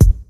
• Old School Short Rap Kick Drum Single Hit F Key 20.wav
Royality free kick tuned to the F note.
old-school-short-rap-kick-drum-single-hit-f-key-20-YJa.wav